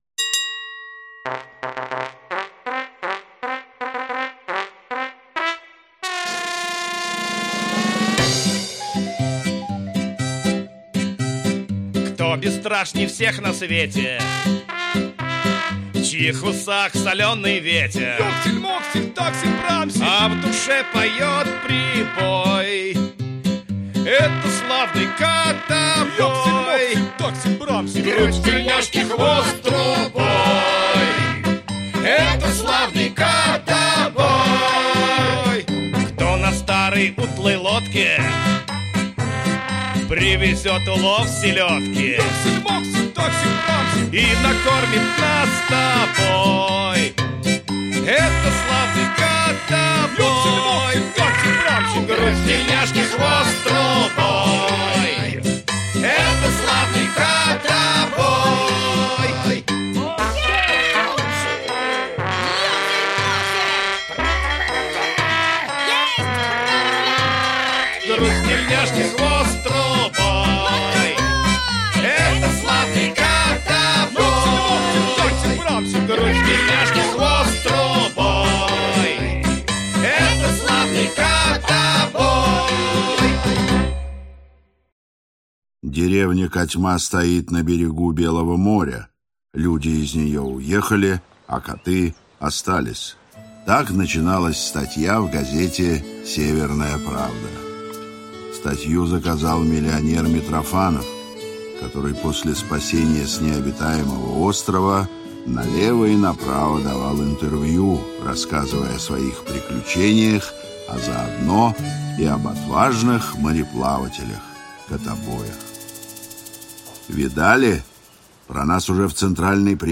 Аудиокнига Украсть «Котобой»! или Полет на Луну | Библиотека аудиокниг
Aудиокнига Украсть «Котобой»! или Полет на Луну Автор Андрей Усачев Читает аудиокнигу Актерский коллектив.